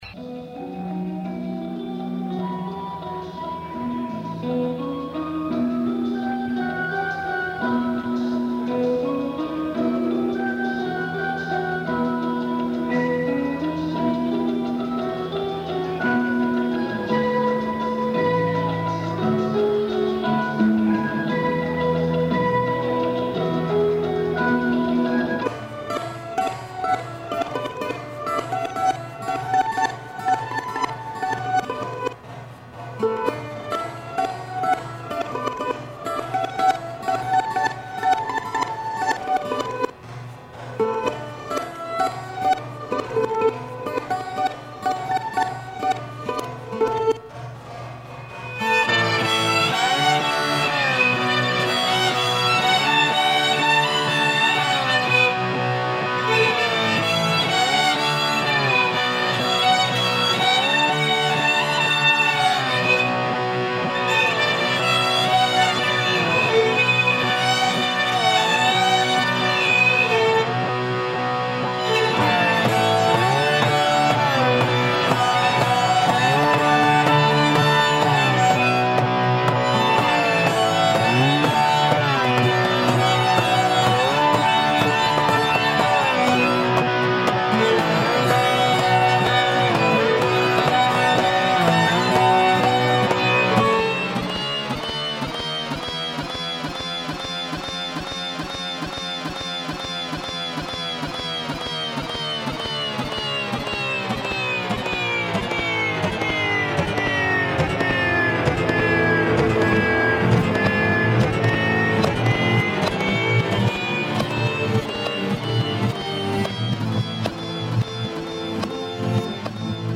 Someone is playing a weird toy piano or something.